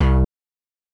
Index of /RoBKTA Sample Pack Supreme/BASSES